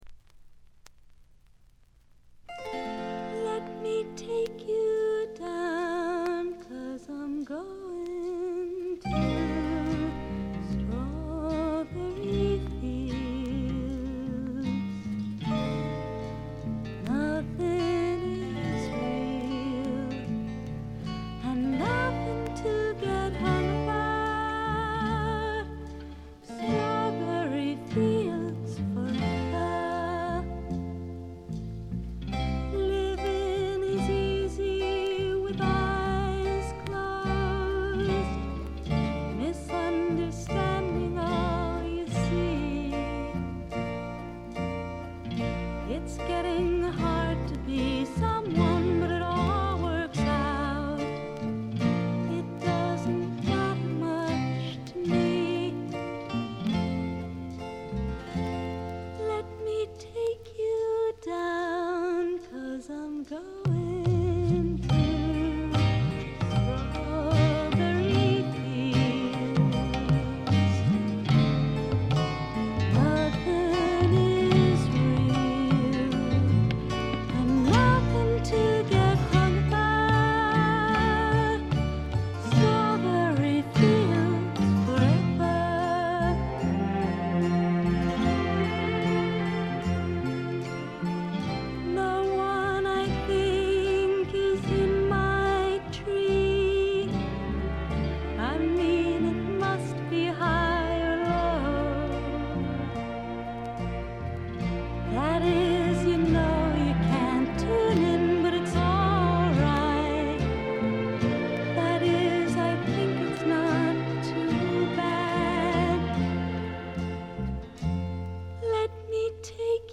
ところどころで軽いチリプチ。
ドリーミーなアメリカン・ガールポップの名作！
試聴曲は現品からの取り込み音源です。